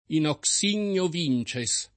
in hoc signo vinces